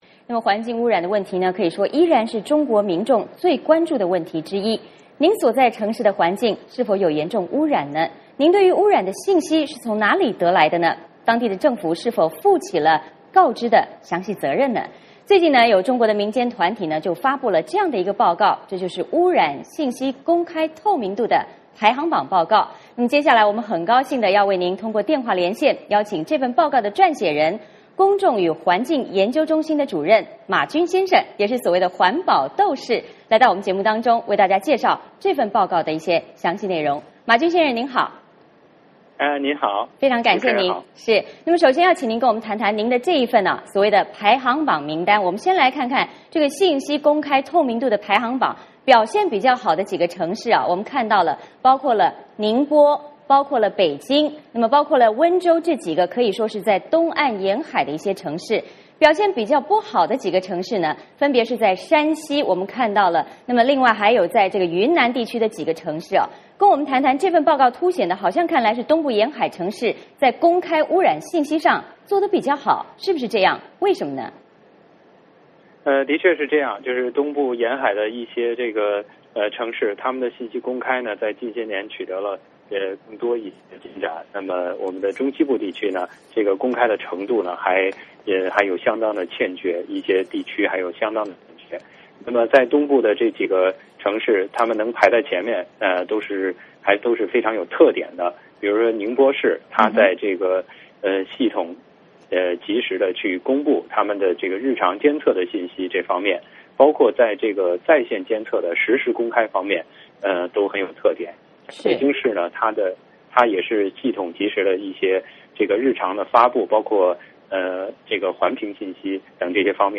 环境污染已经成为中国民众最关注的问题之一，您所在的城市污染严重吗?您对污染状况的了解是从哪里得来的?当地政府是否切实负起告知的责任?最近中国民间环保团体对120个城市进行调查，从这些地方政府公布的数据来评估他们的污染信息透明度，这份报告有哪些发现?污染信息公开对打击污染能带来什么样的帮助?我们通过电话连线，请这份报告的撰写人，中国公众与环境研究中心主任马军来做进一步说明。